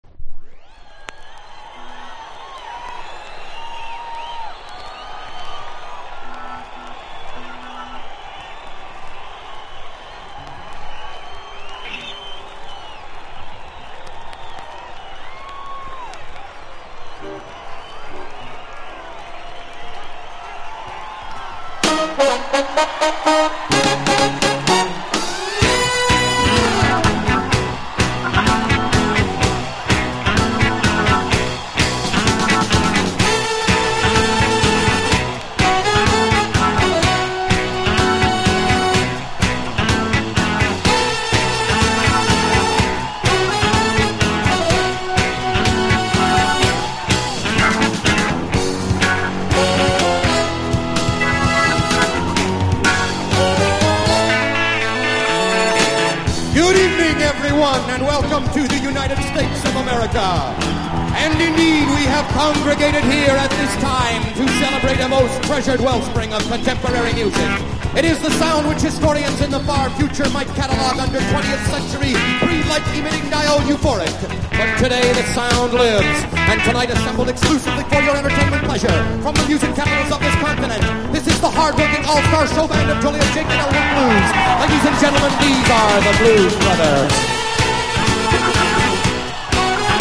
全編ライヴ録音でモータウンなどクラシックのカヴァー集！
RHYTHM & BLUES